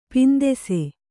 ♪ pindese